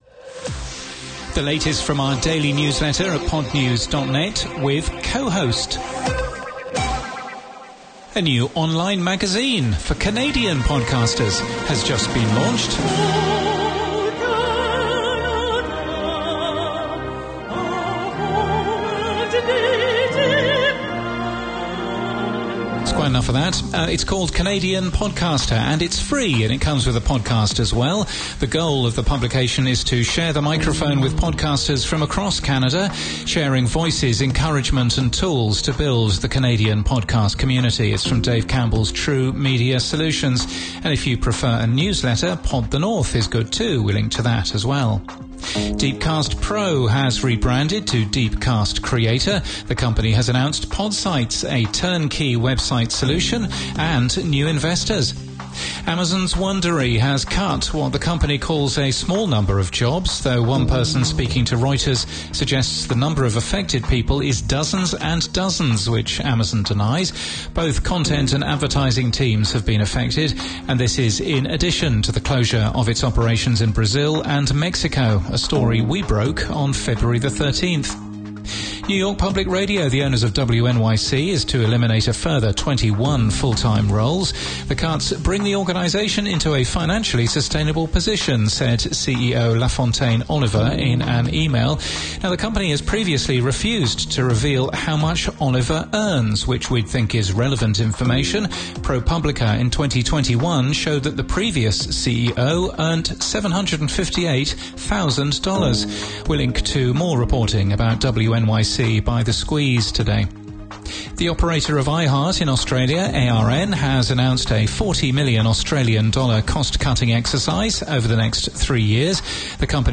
sounds fine - AAC HE v2 in mono actually is quite decent this low bitrate).